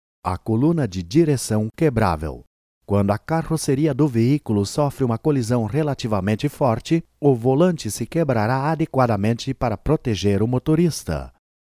葡萄牙语样音试听下载
葡萄牙语配音员（男1）